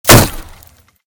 / gamedata / sounds / material / bullet / collide / wood02gr.ogg 16 KiB (Stored with Git LFS) Raw History
wood02gr.ogg